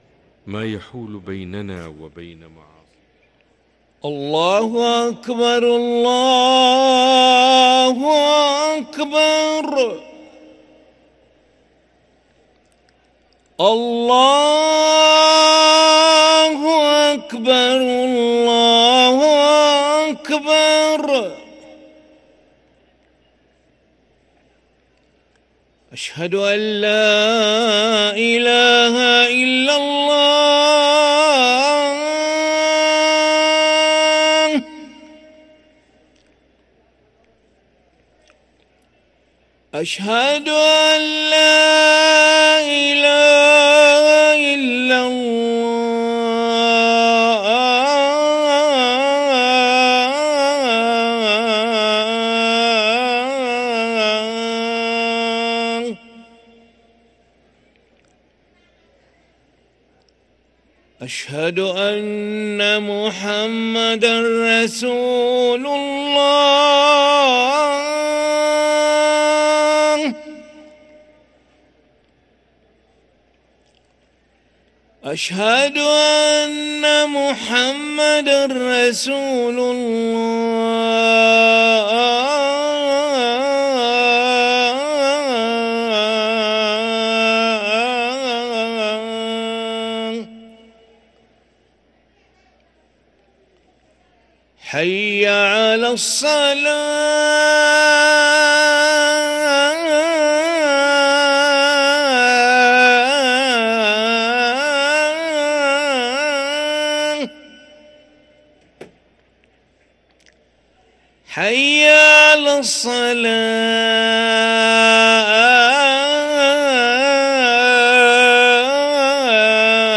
أذان العشاء للمؤذن علي ملا الأحد 13 شعبان 1444هـ > ١٤٤٤ 🕋 > ركن الأذان 🕋 > المزيد - تلاوات الحرمين